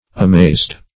amazed - definition of amazed - synonyms, pronunciation, spelling from Free Dictionary